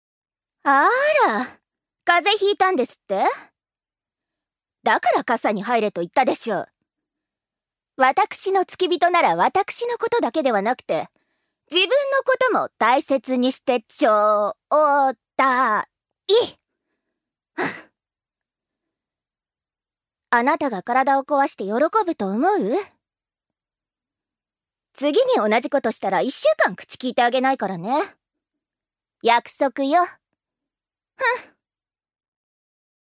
女性タレント
音声サンプル
セリフ３